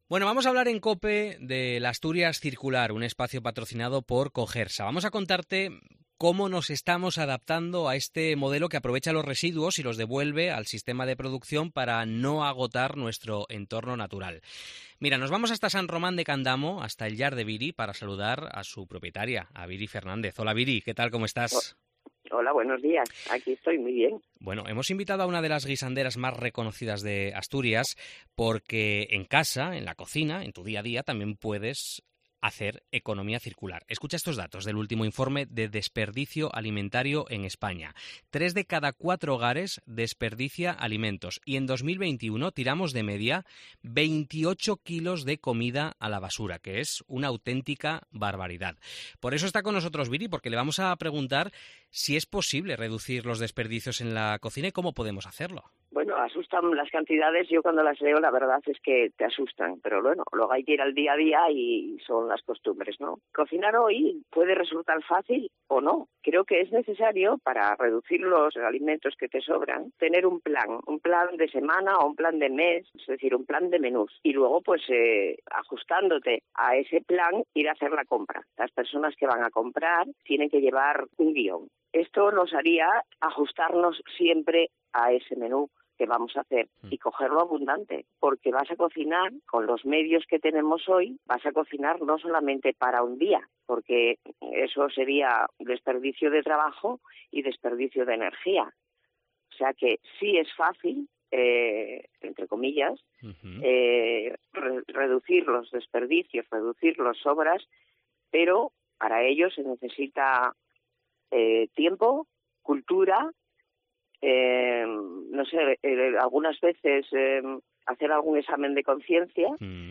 'Podcast de la Asturias Circular': entrevista
Lo haremos a través de la conversación con asturianos y asturianas que son líderes en sus sectores profesionales (hostelería, deporte, moda, arte, cultura, ciencia…) y que además, enarbolan un compromiso claro con el medio ambiente y la sostenibilidad.